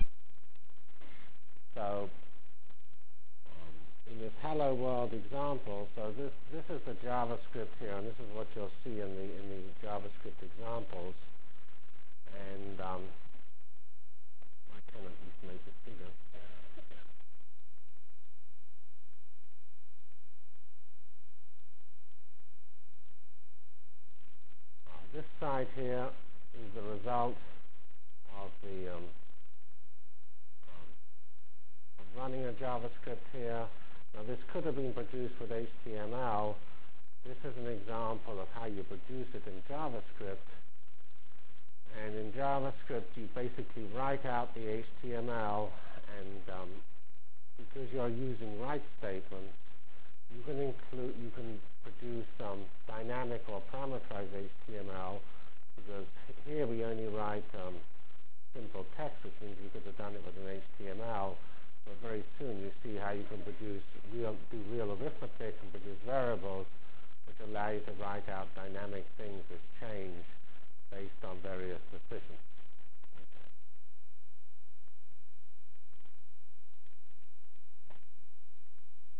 From Feb 10 Delivered Lecture for Course CPS616 -- Introduction to JavaScript CPS616 spring 1997 -- Feb 10 1997. *